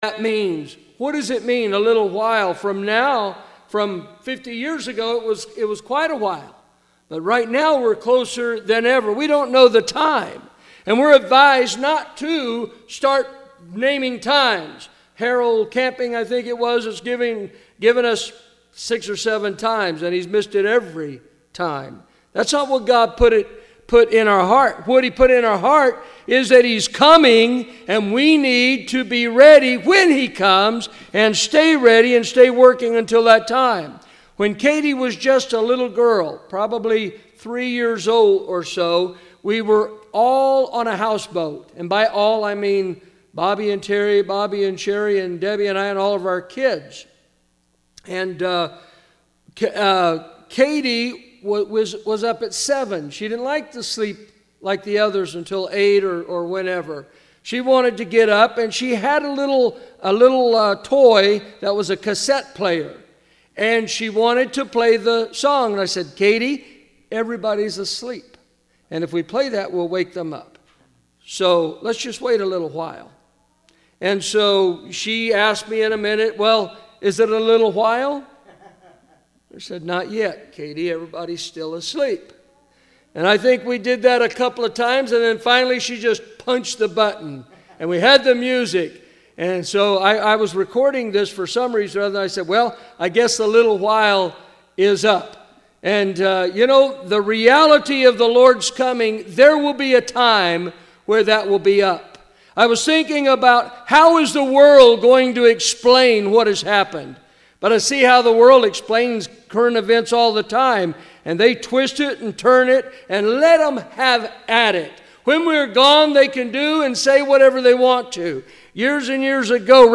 Special Sermons